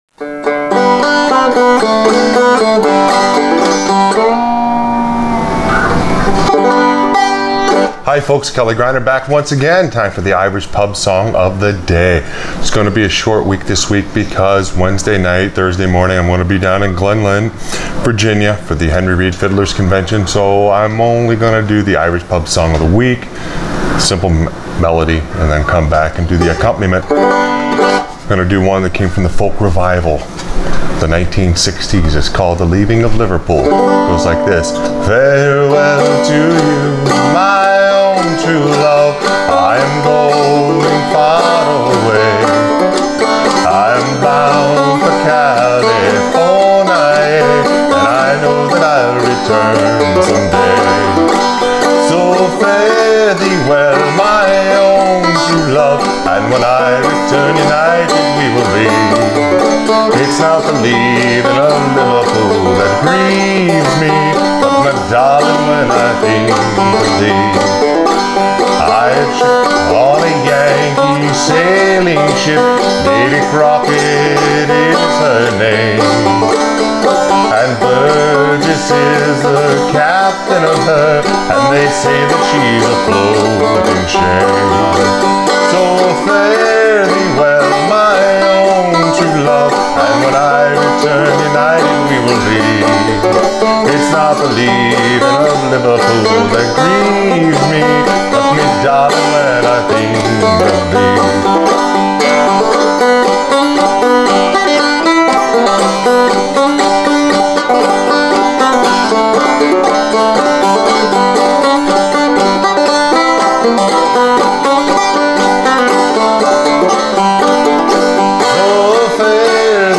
Irish Pub Song Of The Day – The Leaving Of Liverpool on Frailing Banjo